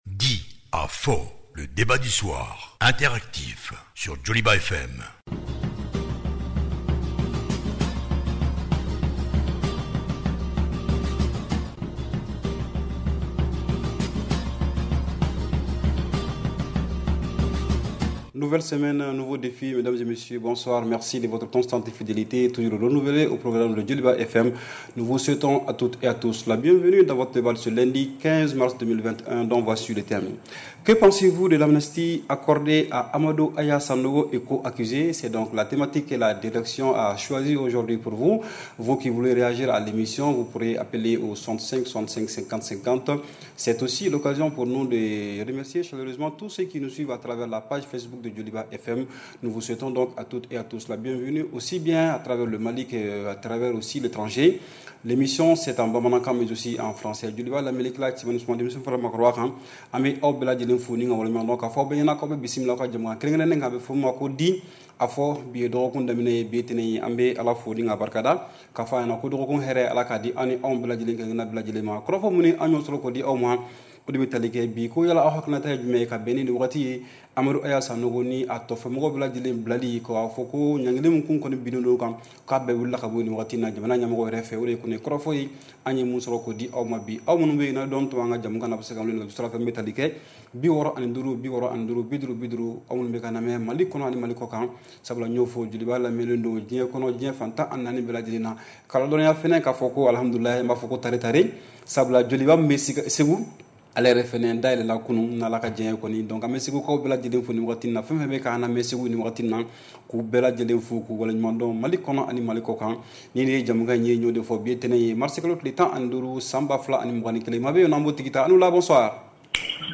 REPLAY 15/03 – « DIS ! » Le Débat Interactif du Soir